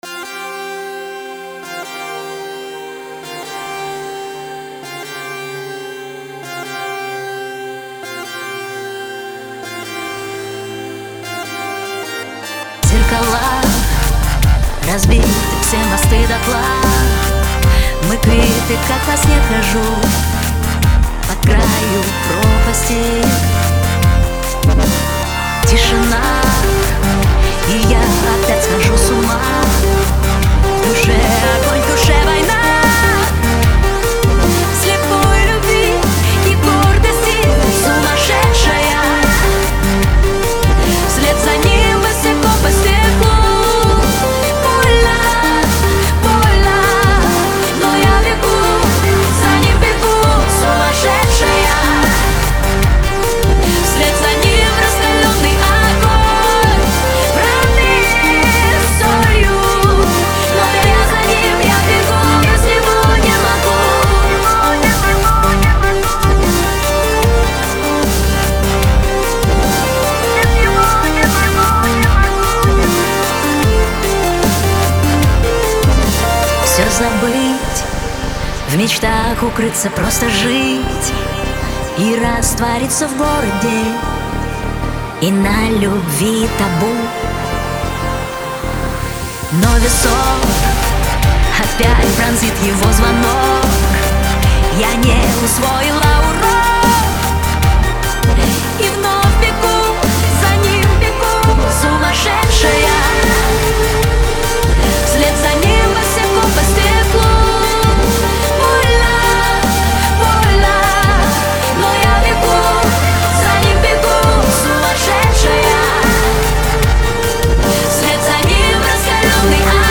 (Ремикс)